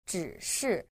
• zhǐshì